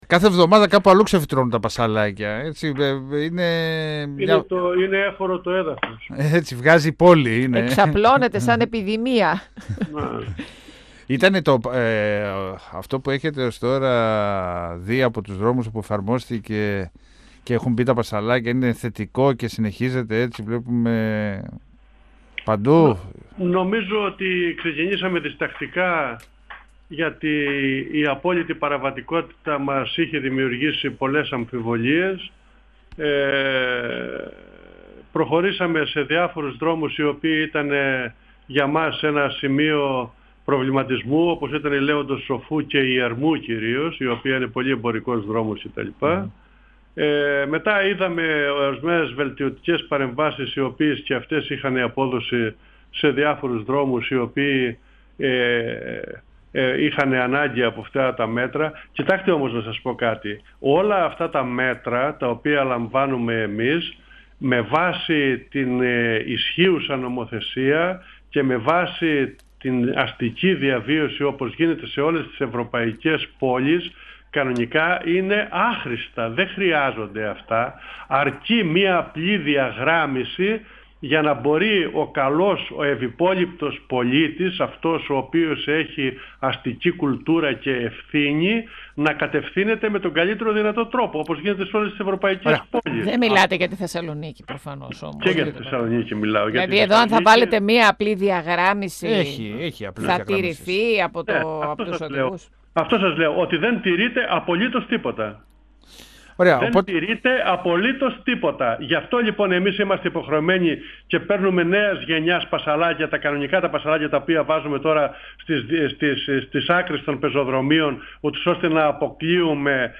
Διαχωριστικά πασαλάκια συνεχίζει να τοποθετεί ο δήμος Θεσσαλονίκης σε αρκετά σημεία της Θεσσαλονίκης σε μια προσπάθεια να αποτραπεί η παράνομη στάθμευση των οχημάτων και να διευκολυνθεί η κυκλοφορία στους δρόμους. Απαραίτητη για να εξασφαλιστεί η εύρυθμη κυκλοφορία πεζών και εποχούμενων χαρακτήρισε την τοποθέτηση των διαχωριστικών ο αντιδήμαρχος τεχνικών έργων, περιβάλλοντος και καθαριότητας του δήμου Θεσσαλονίκης Θανάσης Παπάς μιλώντας στον 102FM του Ραδιοφωνικού Σταθμού Μακεδονίας της ΕΡΤ3.
Συνεντεύξεις